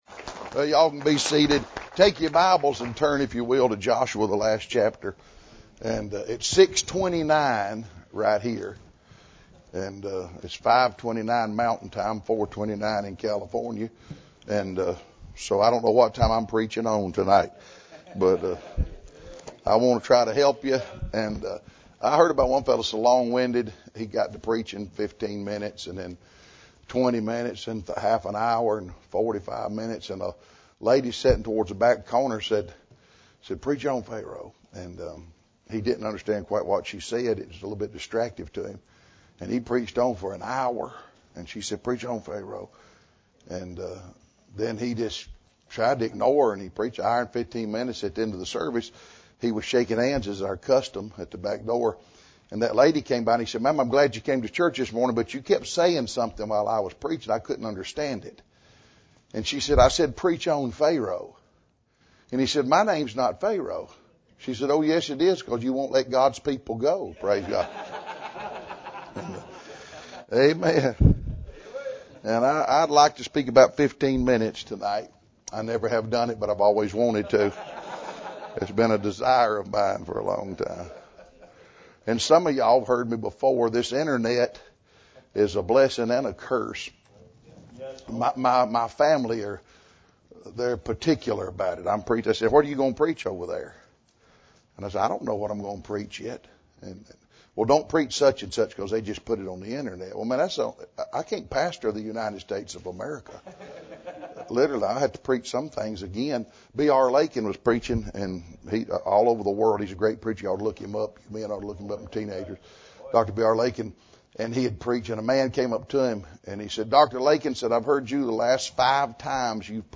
This sermon will unpack three simple but profound reasons Joshua served the Lord — reasons every Christian should know, rehearse, and live by: desire, dedication, and dread of falling away.